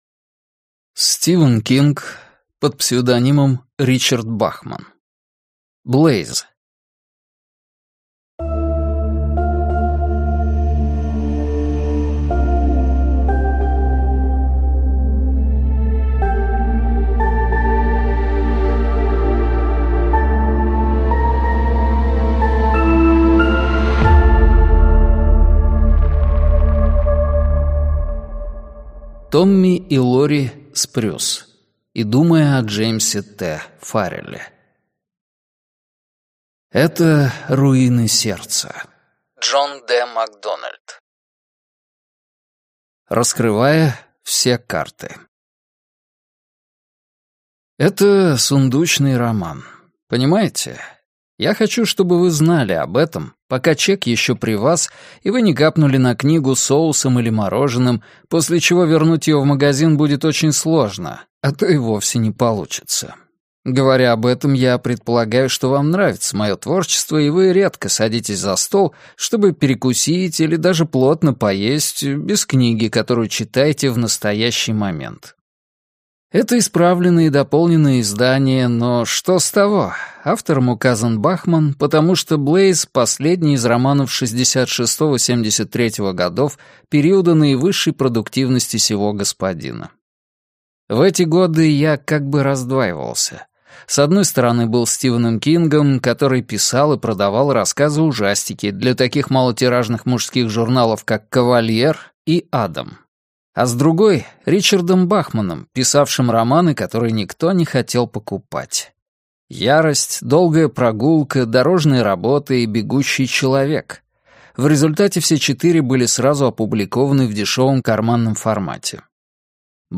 Аудиокнига Блейз (сборник) - купить, скачать и слушать онлайн | КнигоПоиск